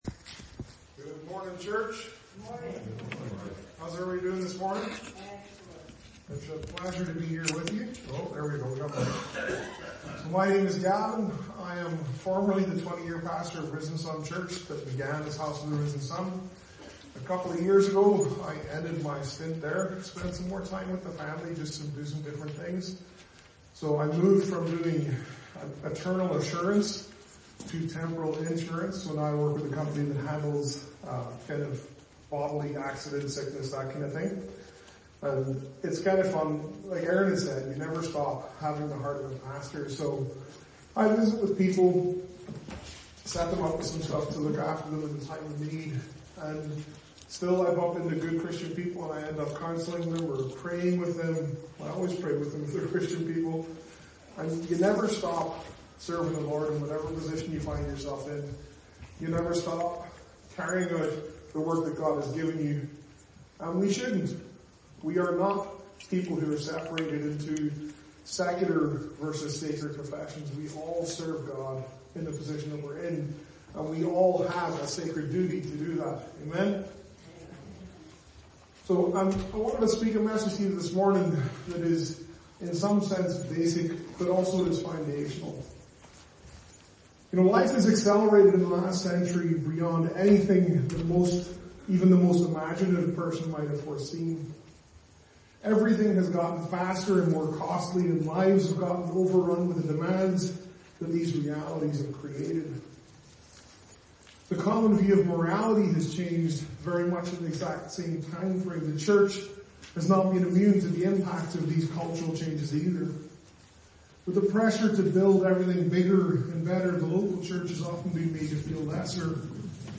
Sermons - Whiteshell Baptist Church